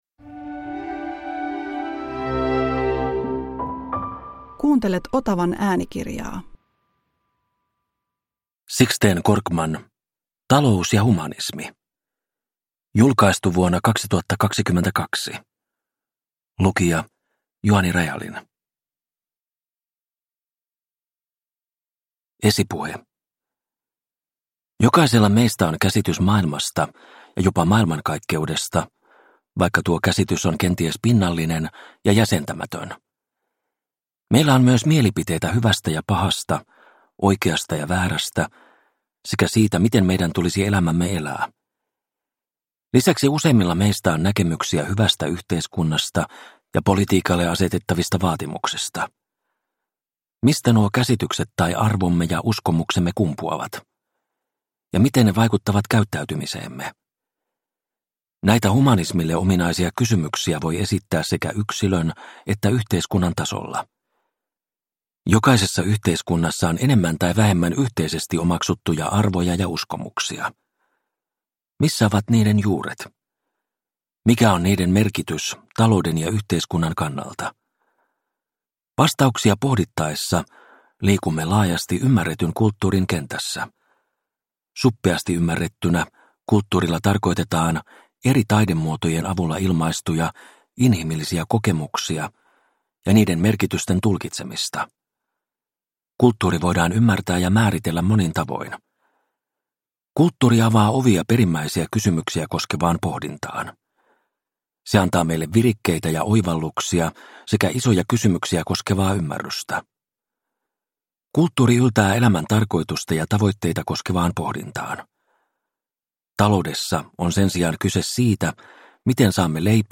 Talous ja humanismi – Ljudbok – Laddas ner